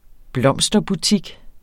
Udtale [ ˈblʌmˀsdʌ- ]